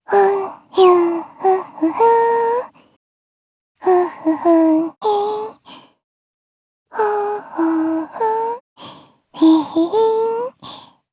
Lewsia_A-Vox_Hum_jp.wav